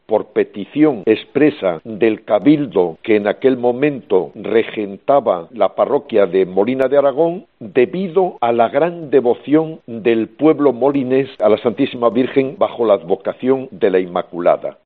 El Obispo Diocesano explica el motivo de la concesión de esta bula.